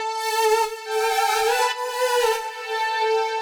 Index of /musicradar/french-house-chillout-samples/140bpm/Instruments
FHC_Pad C_140-A.wav